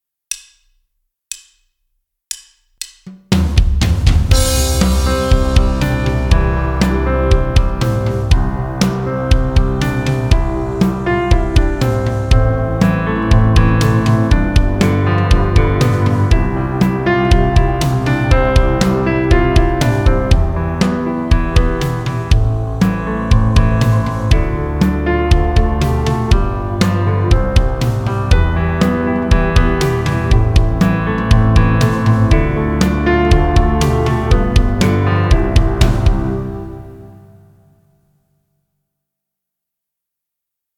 Concert_C_Major_02_.mp3